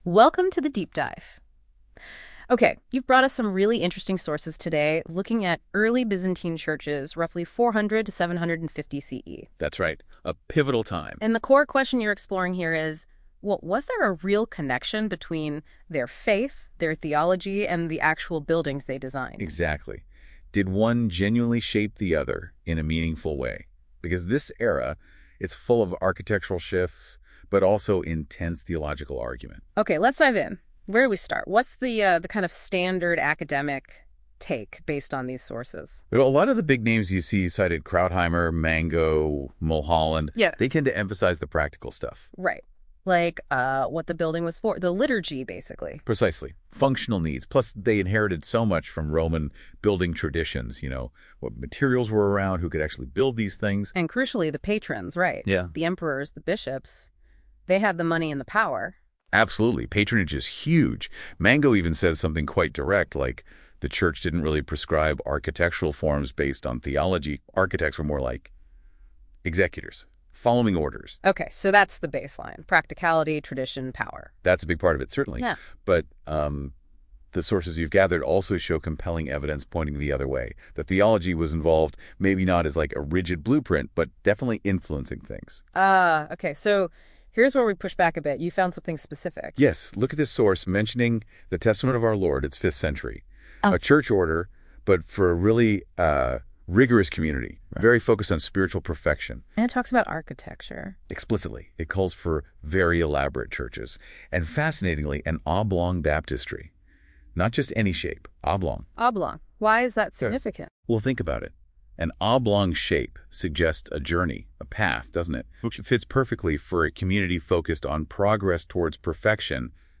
I produced it using Google Gemini AI. It is conversational in form and accessible by a general audience.